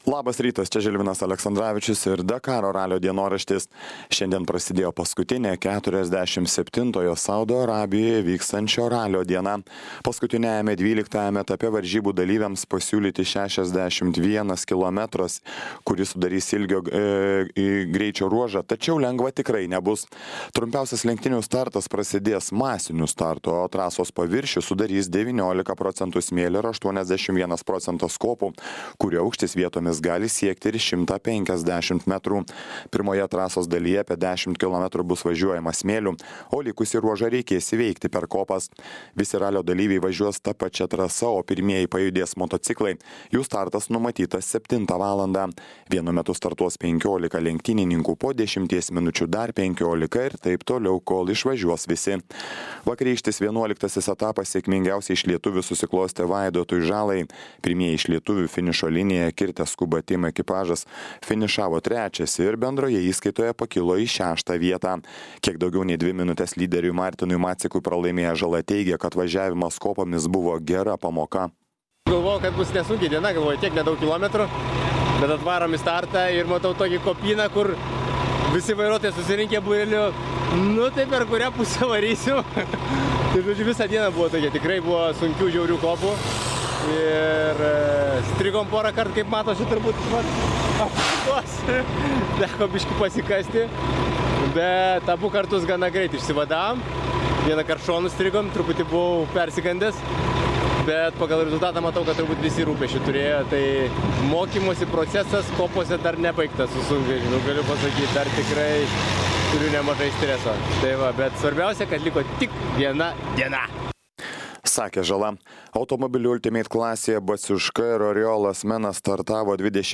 Dakaro ralio apžvalga.